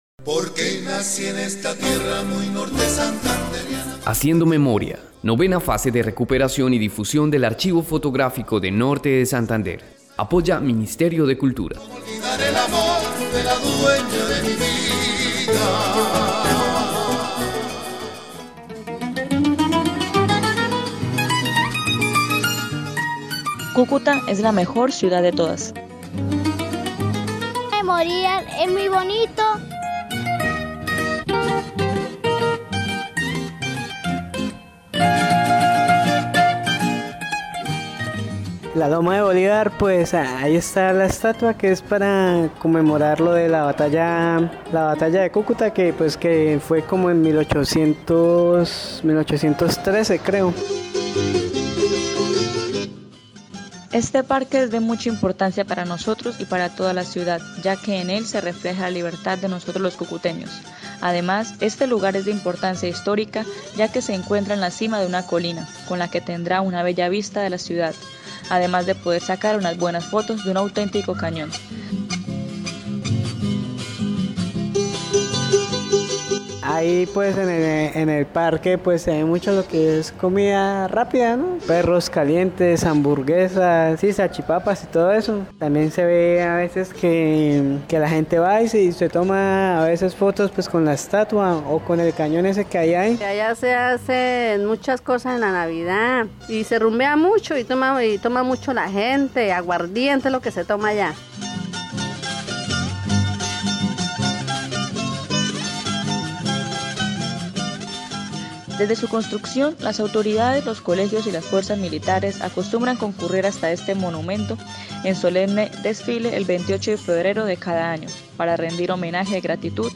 CÚCUTA: Relatar las emociones que produce detenerse y contemplar el monumento a la batalla de Cúcuta y la vista de tan hermosa ciudad, en “La loma de Bolívar”, ese gran Cañón que representa la libertad de los Cucuteños. Los vecinos de este monumento que se encuentra en el barrio que lleva su mismo nombre cuentan sobre la importancia y traen a la memoria fecha tan importante para el departamento.